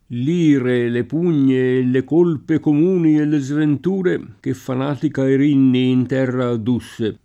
Erinni [er&nni] pers. f. mit.